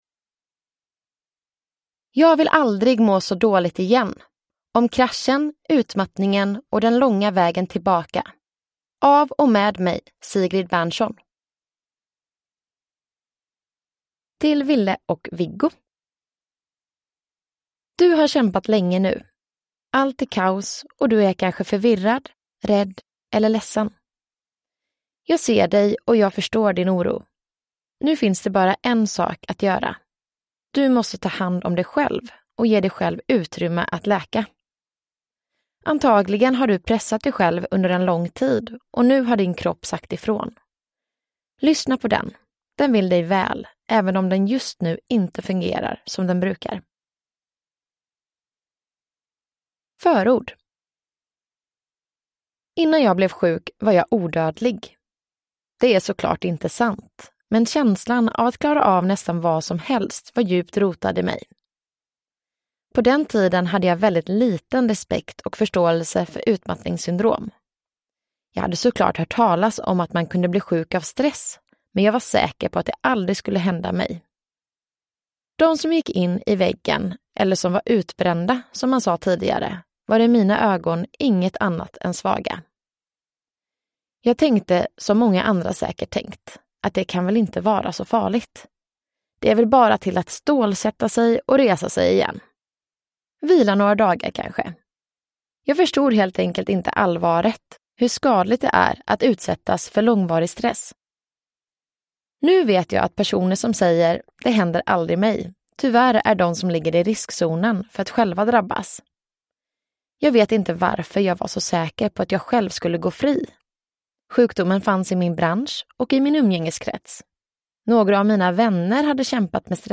Jag vill aldrig må så dåligt igen : om kraschen, utmattningen och den långa vägen tillbaka – Ljudbok
Uppläsare: Sigrid Bernson